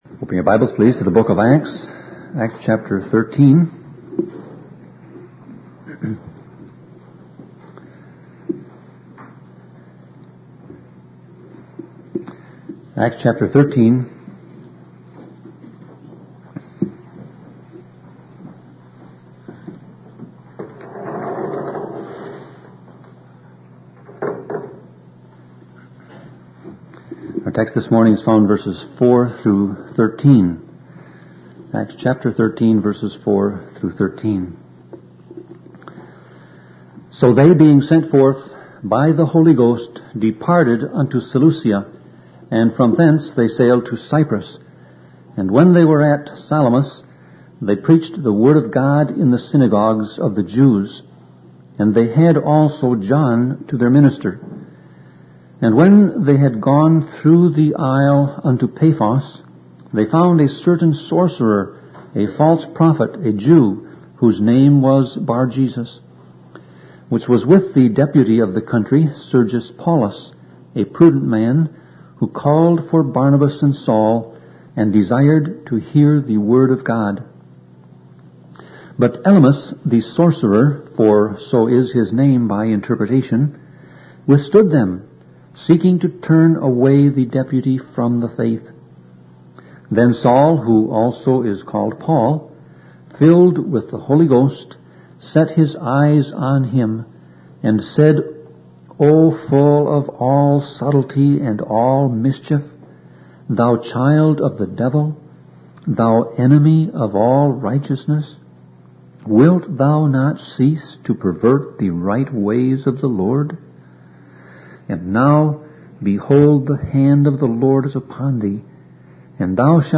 Series: Sermon Audio Passage: Acts 13:4-13 Service Type